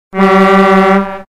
Kirmes Horn